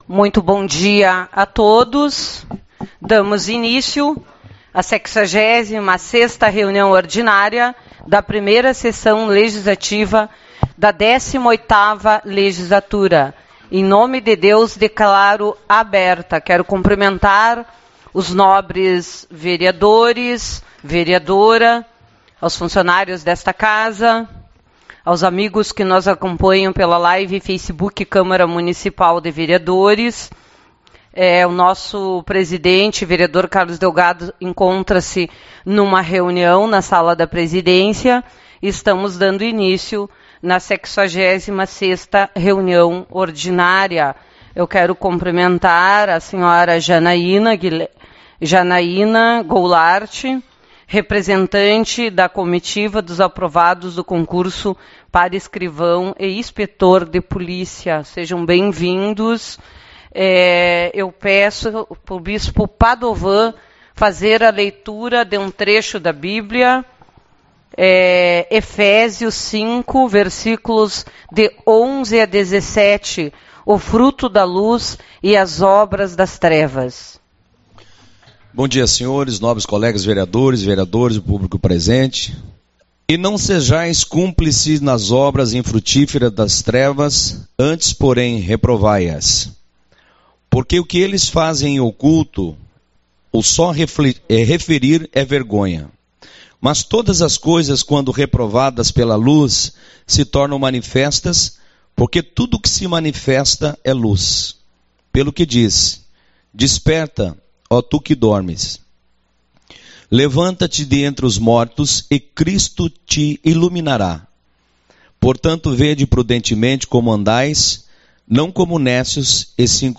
07/10 - Reunião Ordinária